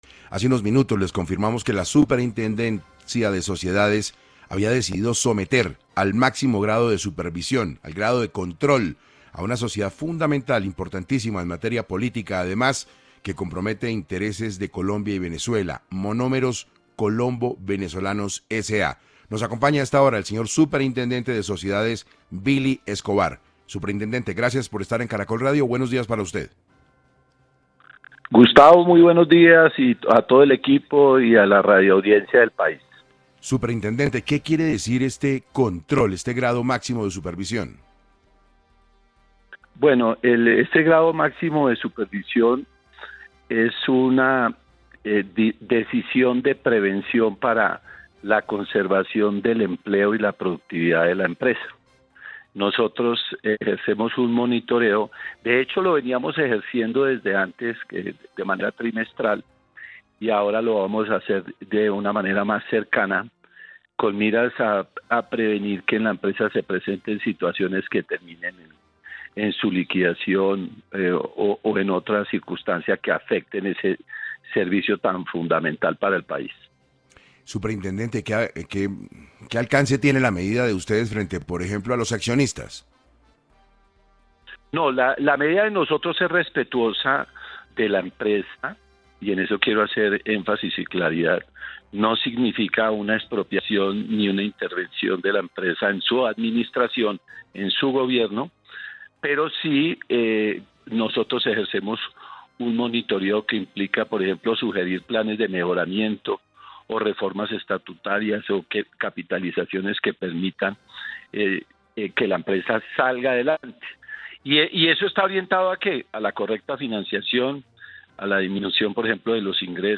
En 6AM de Caracol Radio estuvo Billy Escobar, Superintendente de Sociedades, para hablar sobre este tema y lo que representa para Monómeros que la Supersociedades la someta al máximo grado de supervisión.